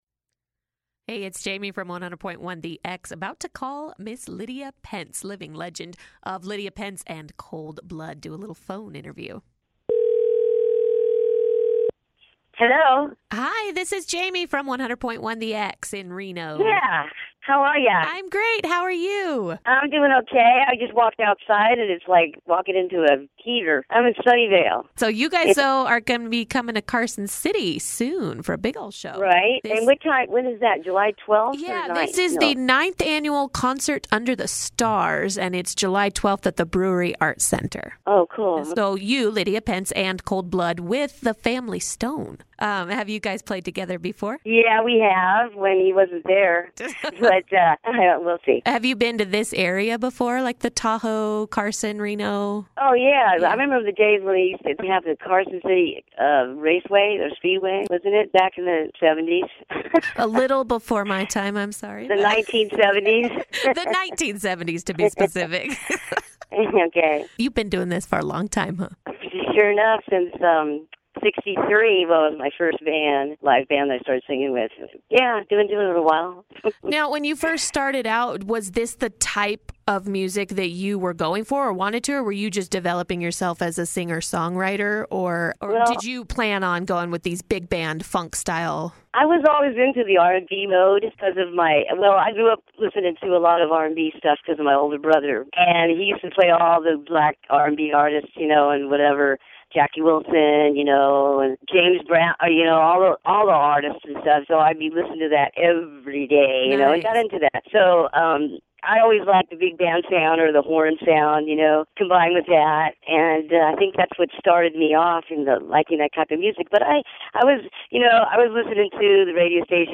Interview w/ Lydia Pense